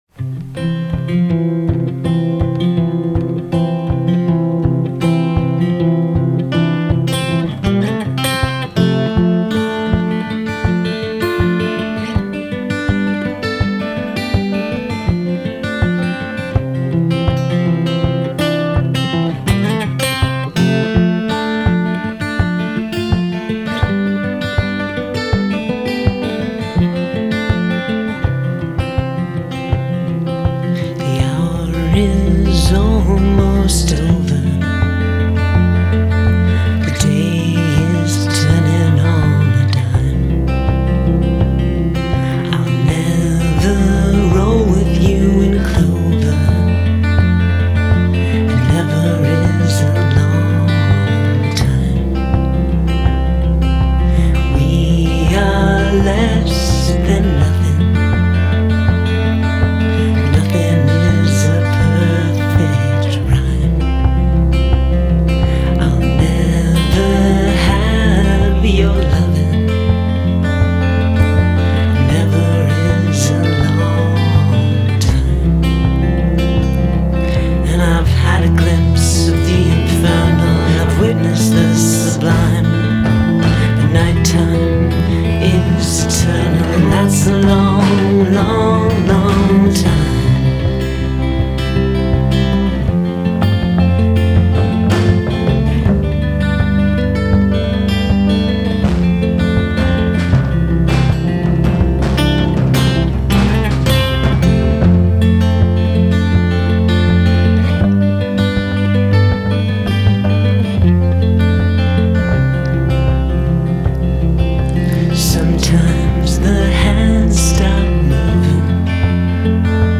Genre: Indie, Alternative